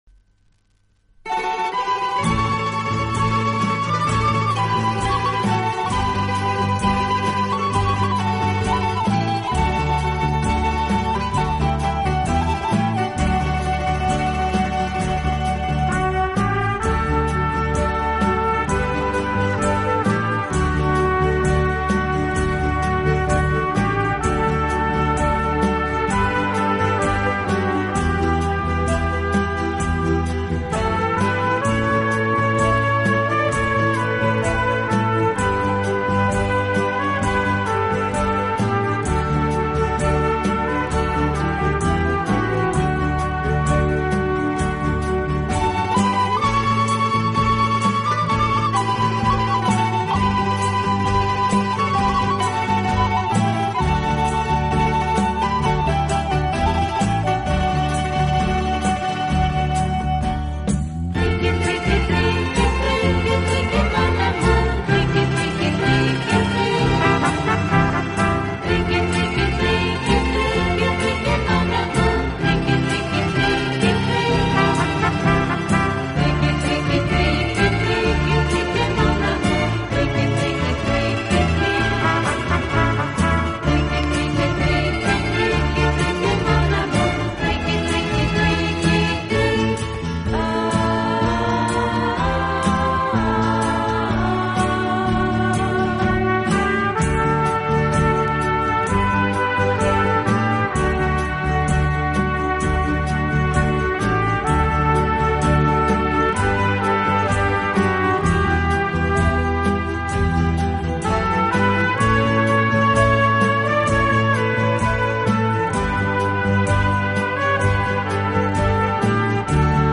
演奏轻柔优美，特別是打击乐器的演奏，具有拉美音乐独特的韵味。